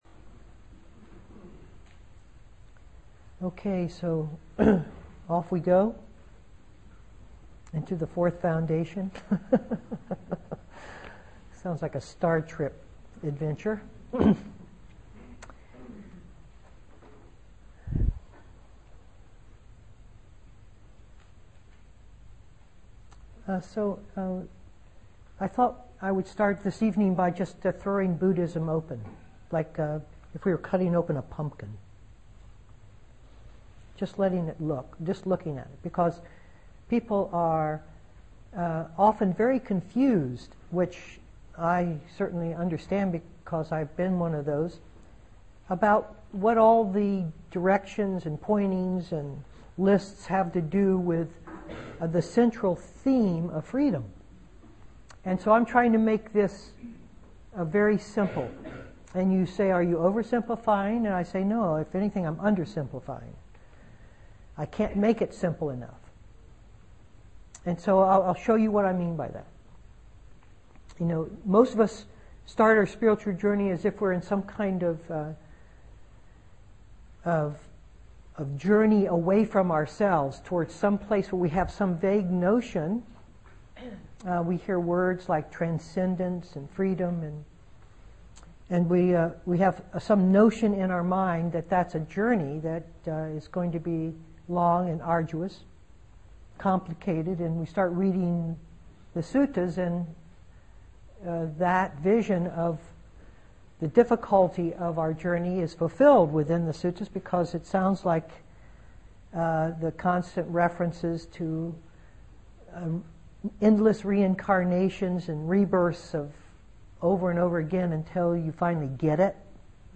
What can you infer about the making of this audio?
2011-11-22 Venue: Seattle Insight Meditation Center